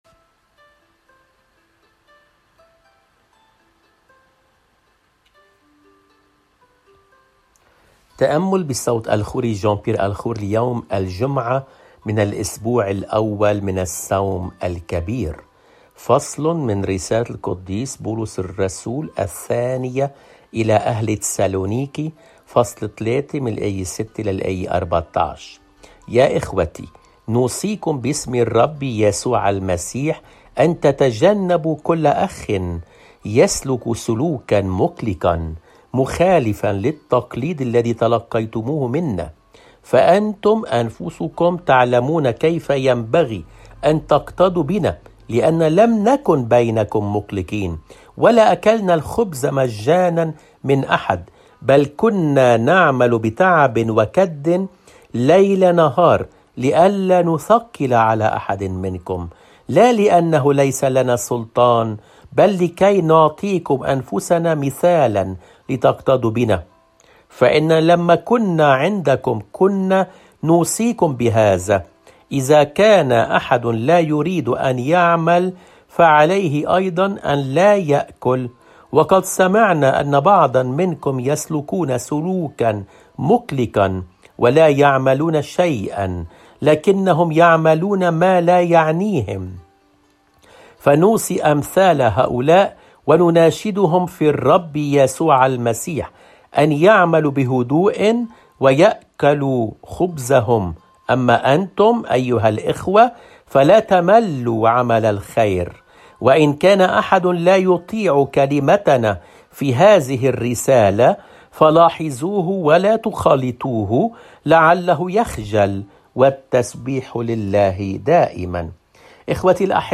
الرسالة